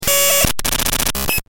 دانلود آهنگ موج 4 از افکت صوتی طبیعت و محیط
دانلود صدای موج 4 از ساعد نیوز با لینک مستقیم و کیفیت بالا
جلوه های صوتی